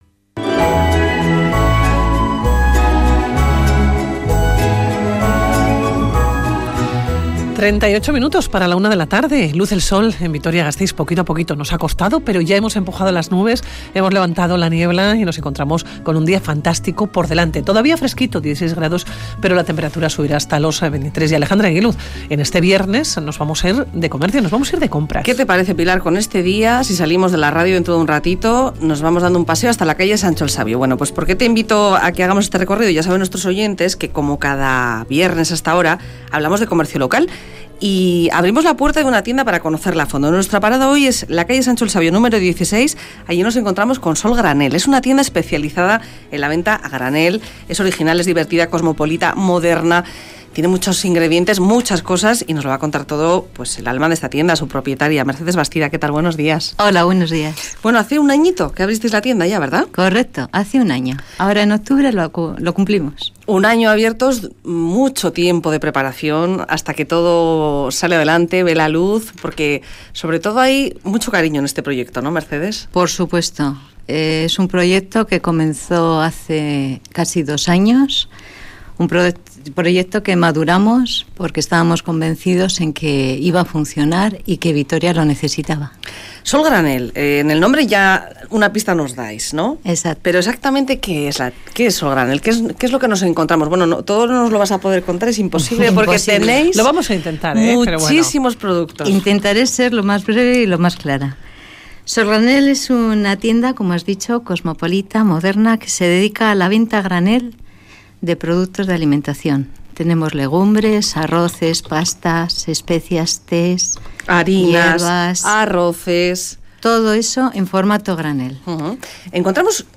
Entrevista Solgranel